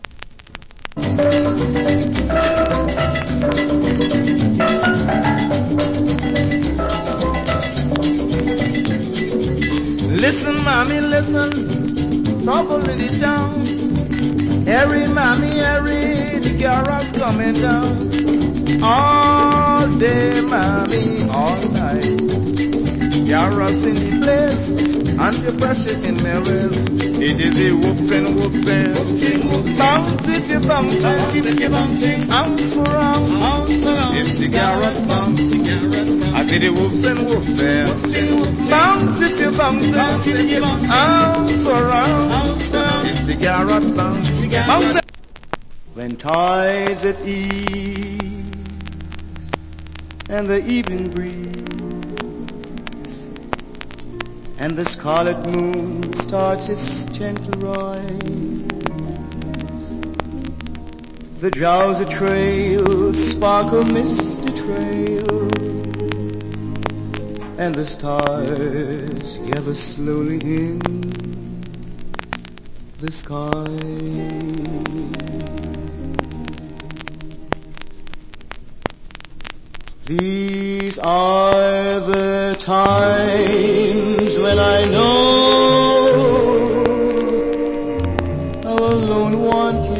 Nice Steel Drums Calypso Vocal.